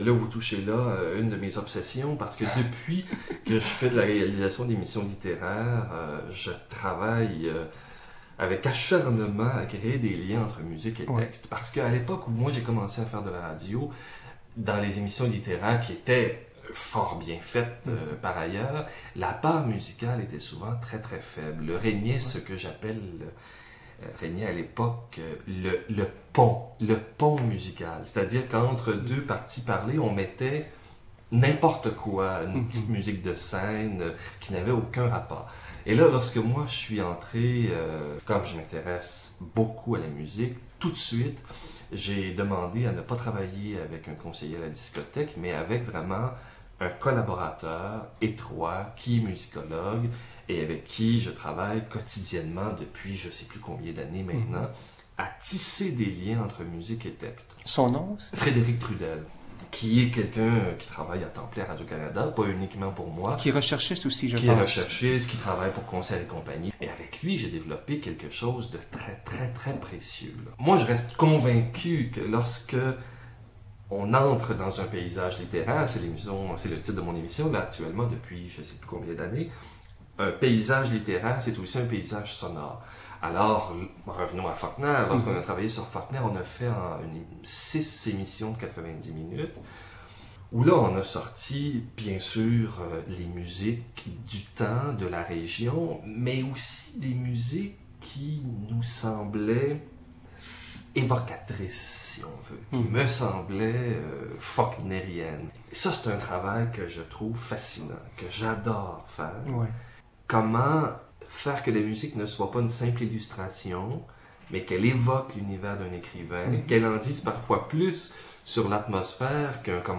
Résumé de l'entrevue :